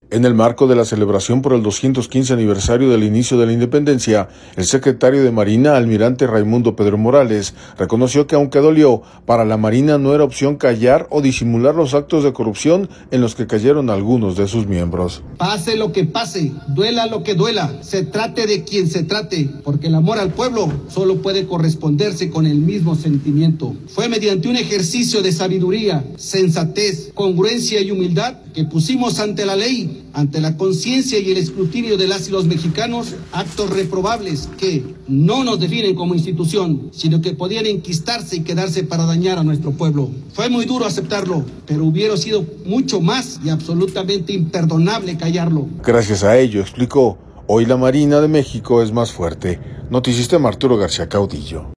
En el marco de la celebración por el 215 aniversario del inicio de la Independencia, el secretario de Marina, almirante Raymundo Pedro Morales, reconoció que, aunque dolió, para la Marina no era opción callar o disimular los actos de corrupción en los que cayeron algunos de sus miembros.